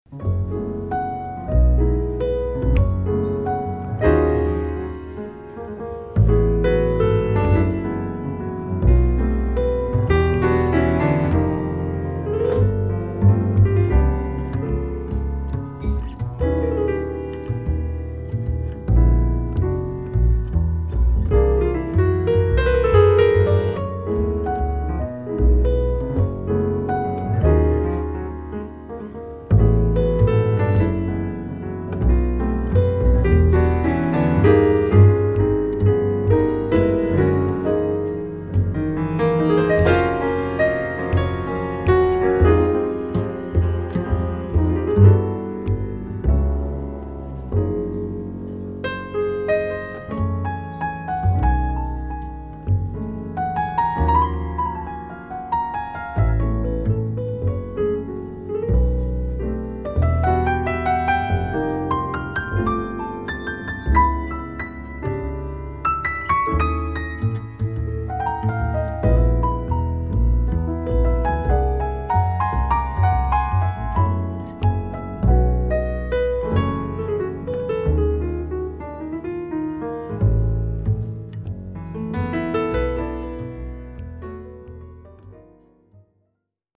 Recorded live at Keystone Korner, San Francisco, July, 1981